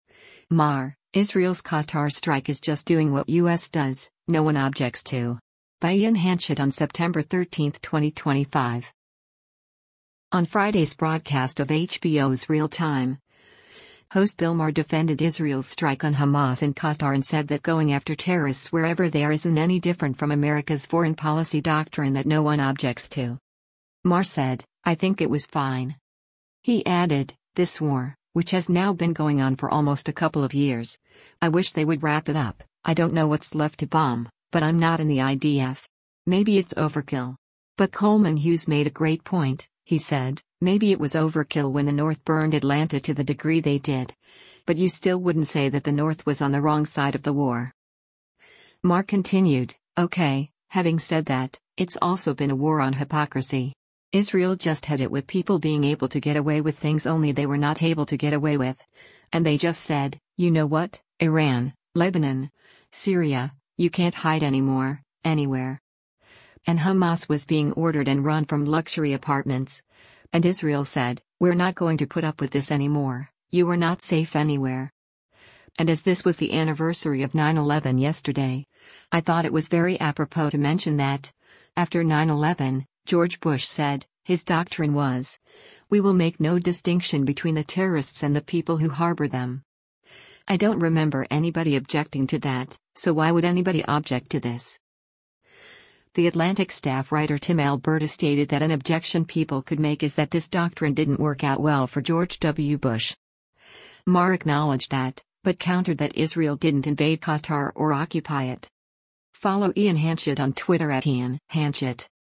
On Friday’s broadcast of HBO’s “Real Time,” host Bill Maher defended Israel’s strike on Hamas in Qatar and said that going after terrorists wherever they are isn’t any different from America’s foreign policy doctrine that no one objects to.
The Atlantic Staff Writer Tim Alberta stated that an objection people could make is that this doctrine didn’t work out well for George W. Bush.